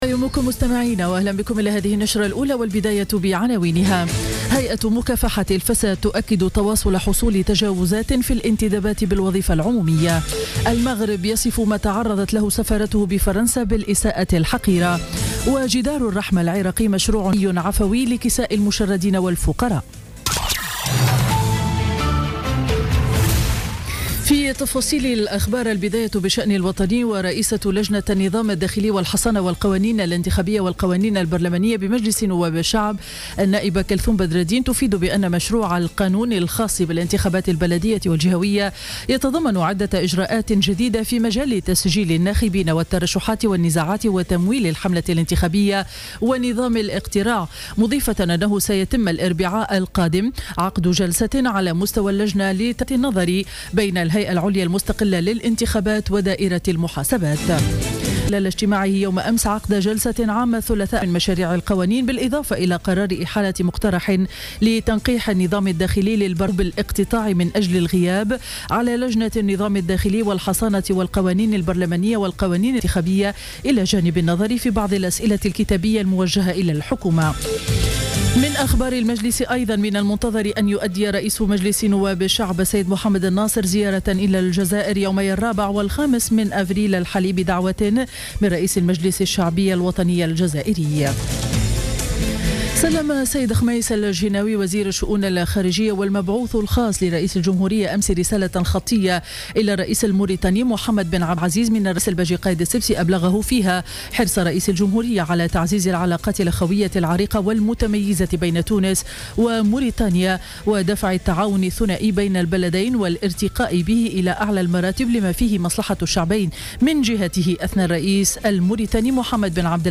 نشرة أخبار السابعة صباحا ليوم الجمعة 1 أفريل 2016